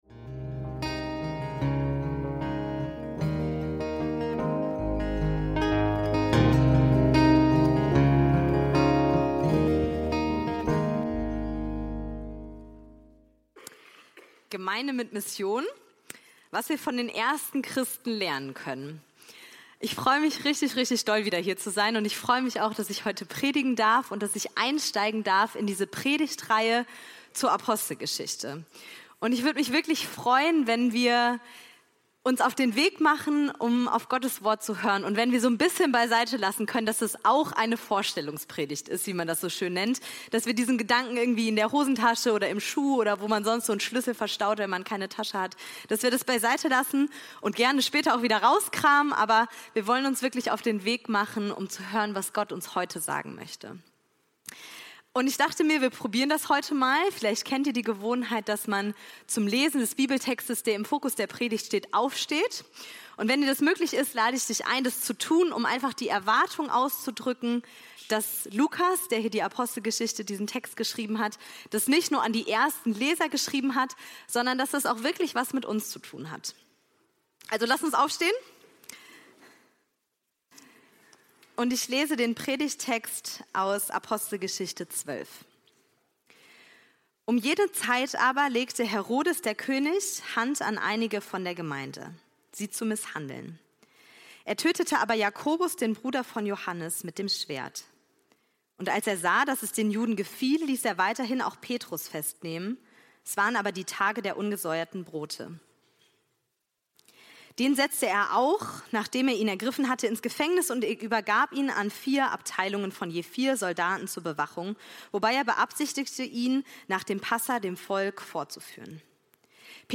Leiden und Beten – Gemeinde mit Mission – Predigt vom 22.02.2026